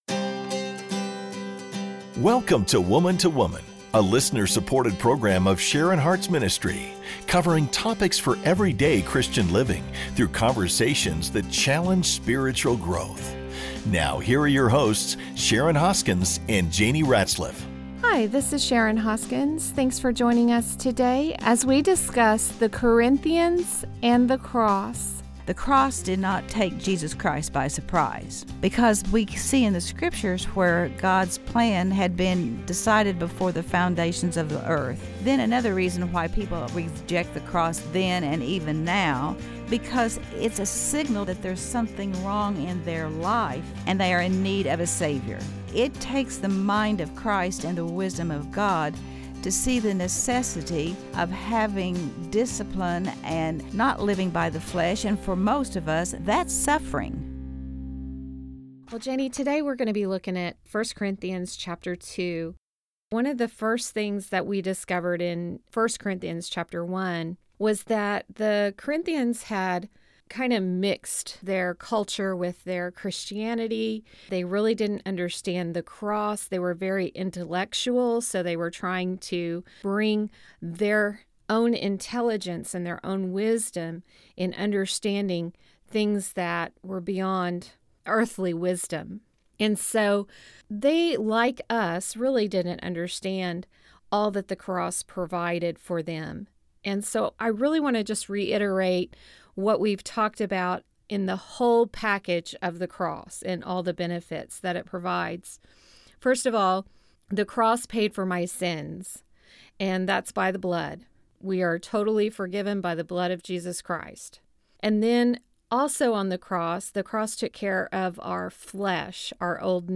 So join in the conversation as we discuss the Cross and the Corinthians.